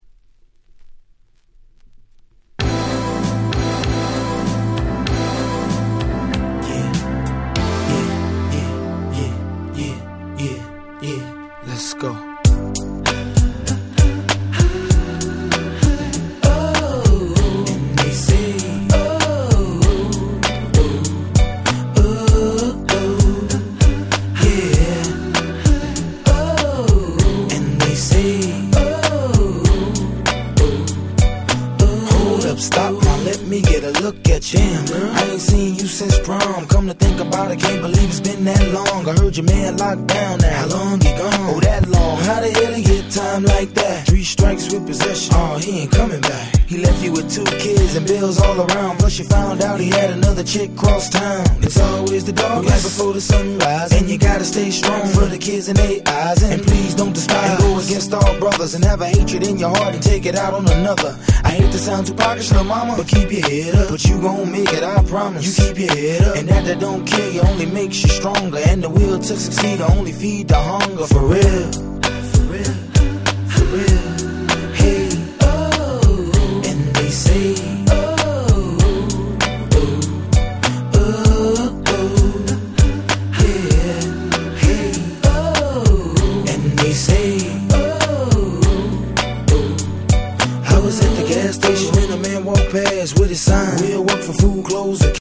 05' Smash Hit Hip Hop !!
キャッチー系